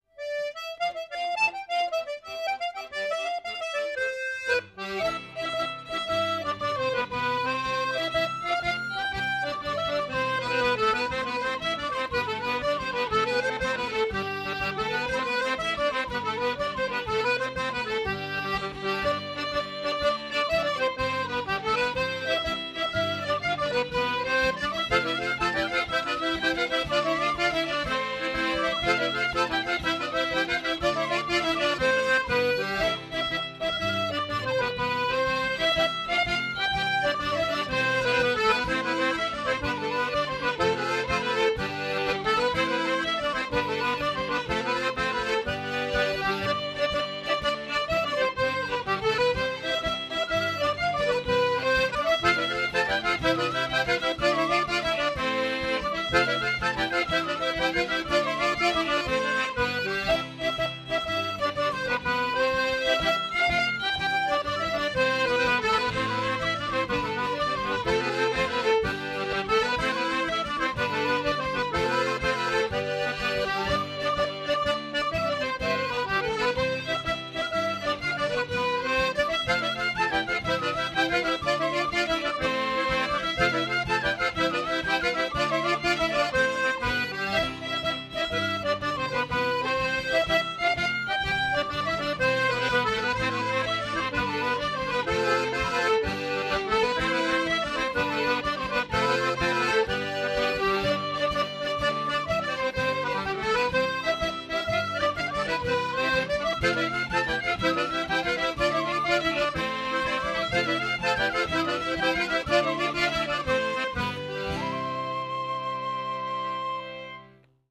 Polesana (Veneto)                  descrizione